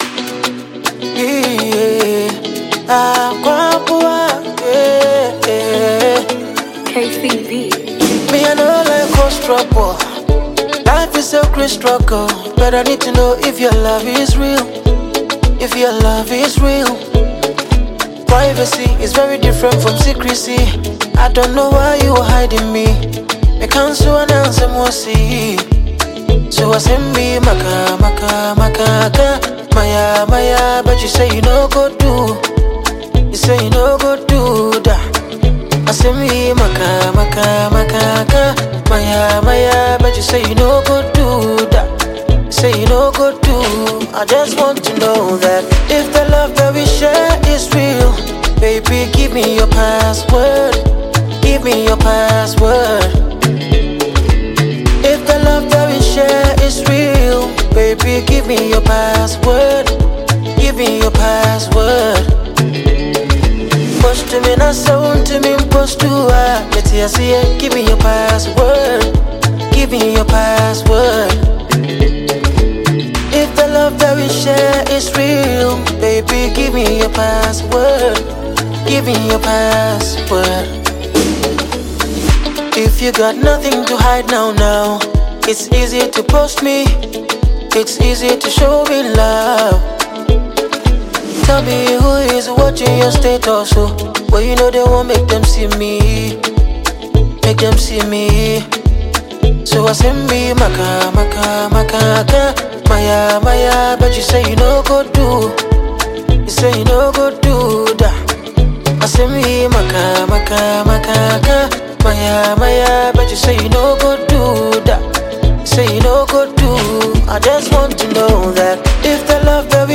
Ghanaian highlife artist